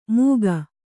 ♪ mūga